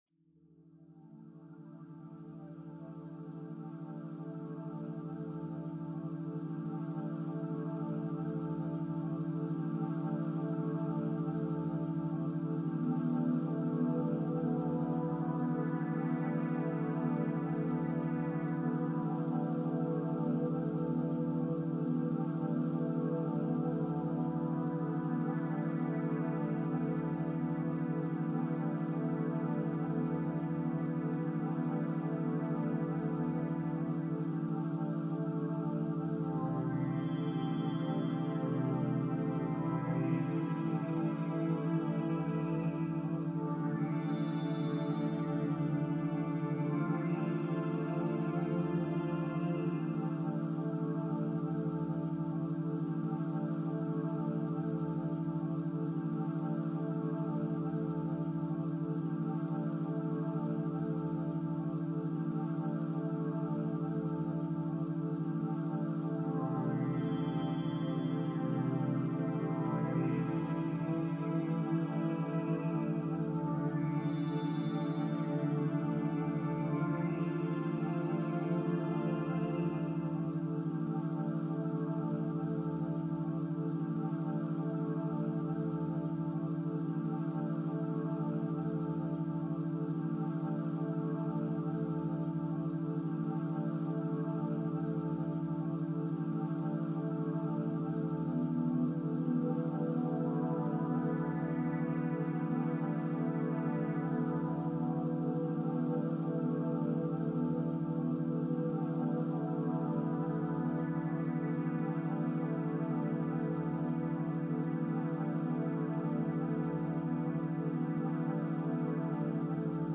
Root Chakra Healing – 303 Hz Meditation for Grounded Focus and Stability